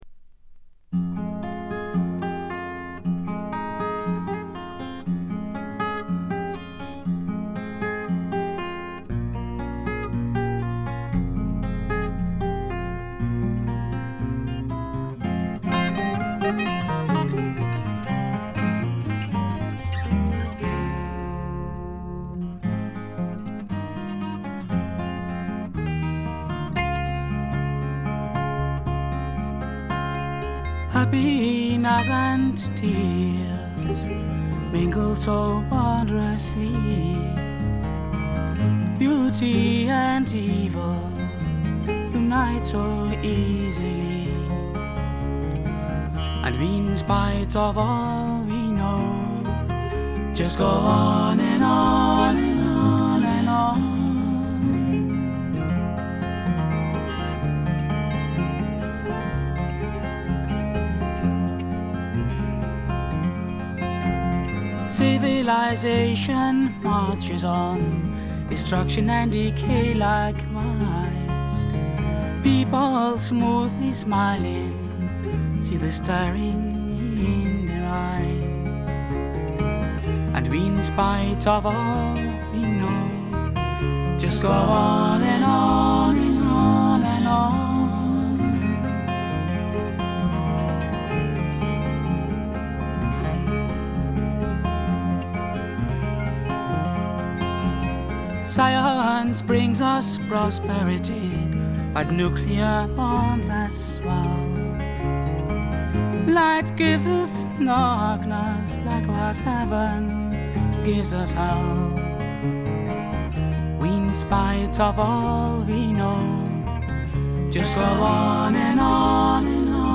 軽いチリプチが少々。
哀愁のメロディに美しいことこの上ないアコースティックギターの調べ。
全体を貫く哀愁味、きらきらとしたアコースティックな美しさは文句なしに至上のもの。
試聴曲は現品からの取り込み音源です。